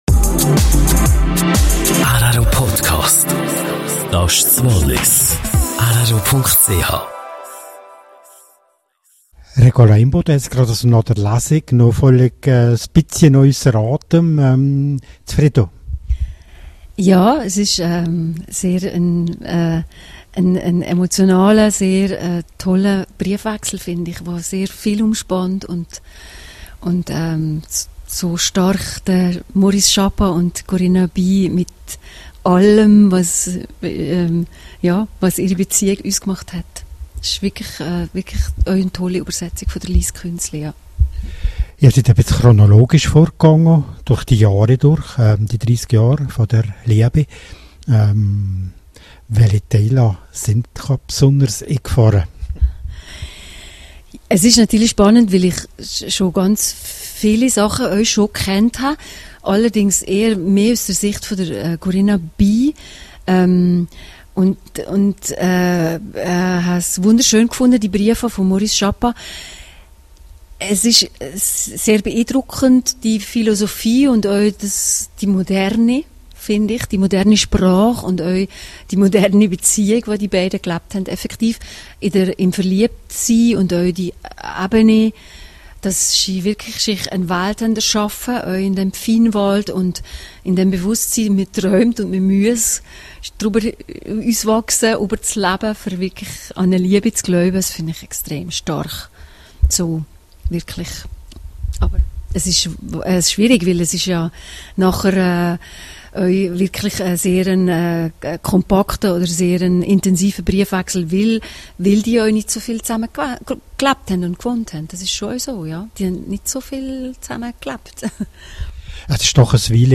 Die beiden Schauspieler verliehen dem Briefwechsel eine einfühlsame Interpretation.